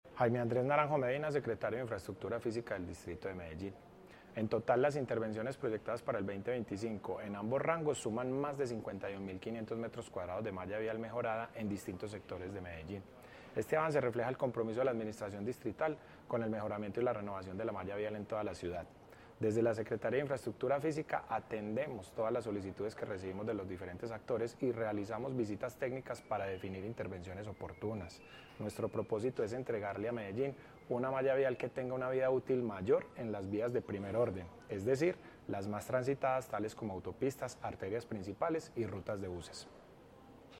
Declaraciones-del-secretario-de-Infraestructura-Fisica-Jaime-Andres-Naranjo-Medina.mp3